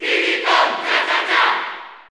Crowd cheers (SSBU) You cannot overwrite this file.
Diddy_Kong_Cheer_Spanish_NTSC_SSB4_SSBU.ogg